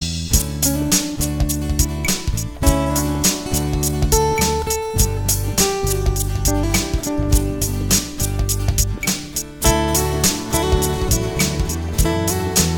Músico, Arreglista, Guitarrista y Compositor